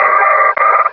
Cri de Tengalice dans Pokémon Rubis et Saphir.